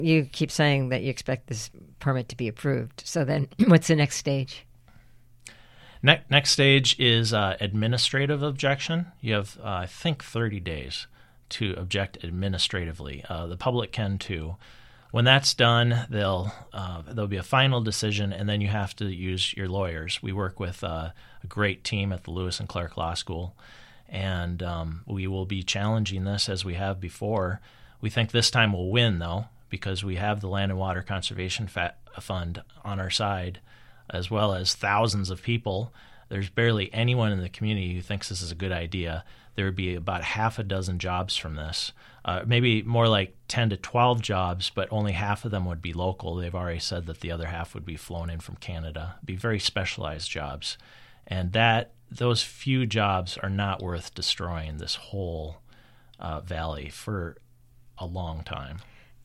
KBOO Mine Interview